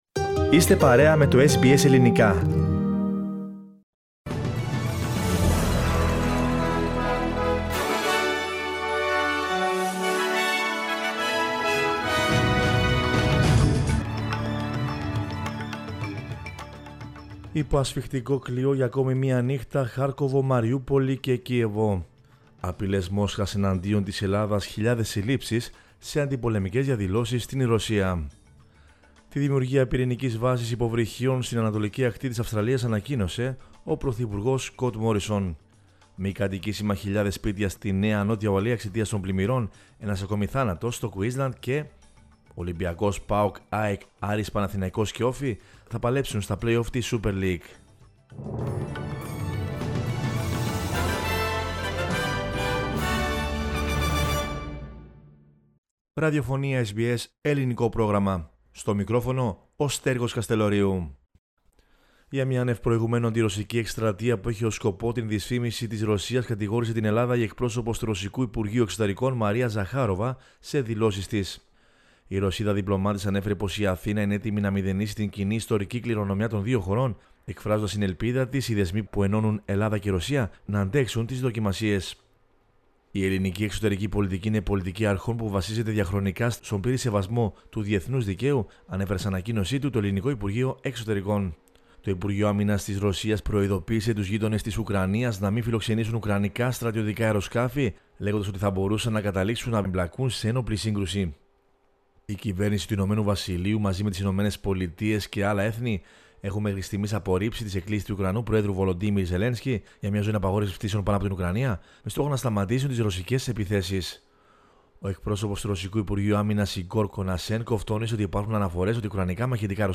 News in Greek from Australia, Greece, Cyprus and the world is the news bulletin of Monday 7 March 2022.